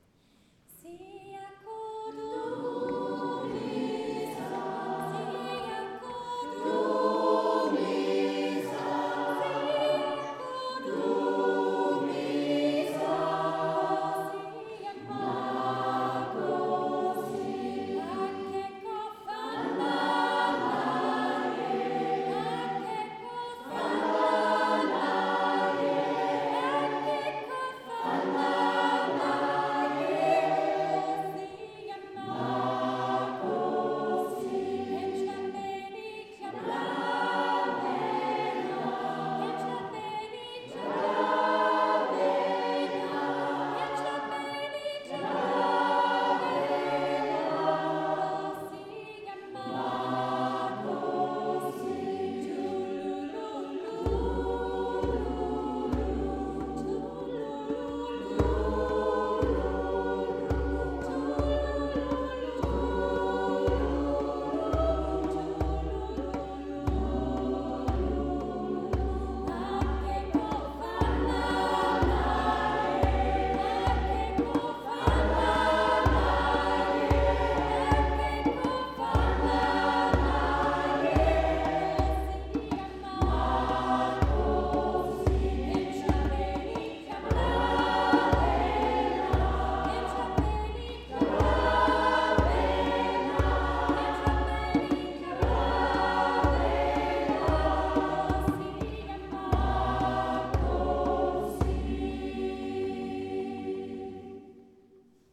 Mit rhythmischen Bewegungen zu afrikanischen Liedern begeisterte der Chor unserer Pfarre
Die afrikanischen Lieder aus dem Gottesdienst